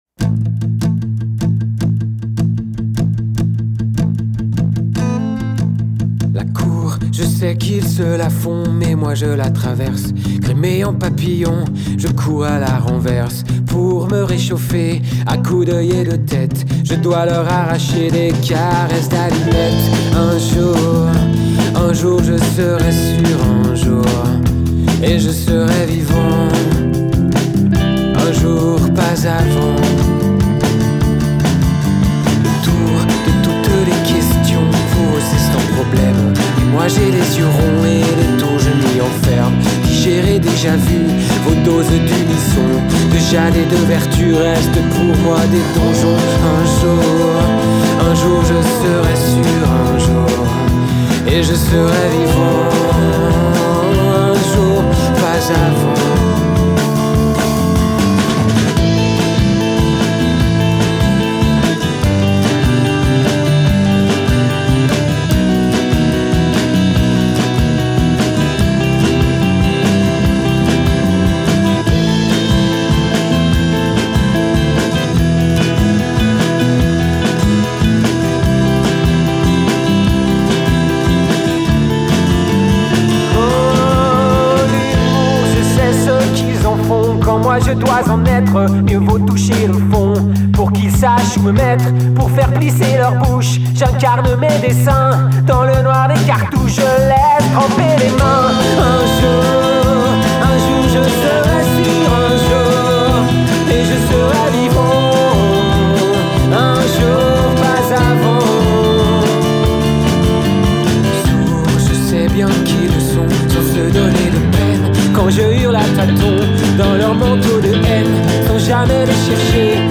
rock français
Prise de son « live »